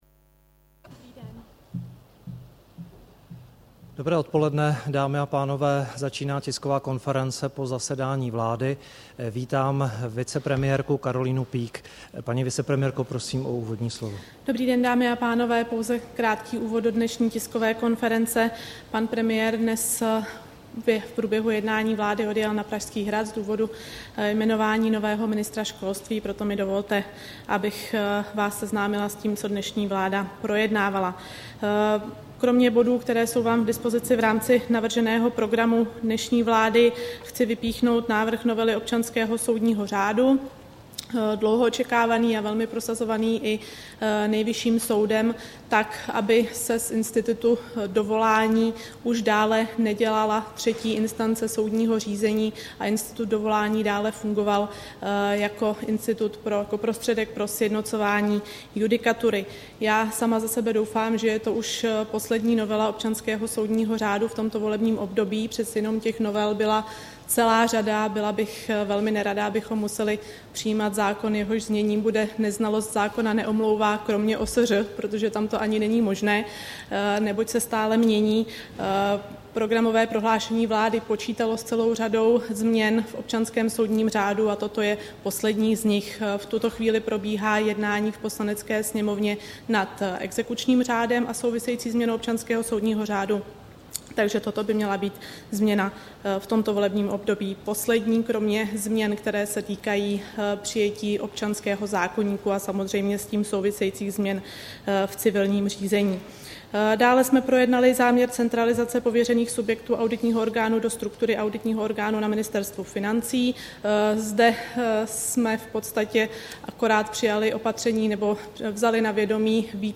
Tisková konference po jednání vlády, 2. května 2012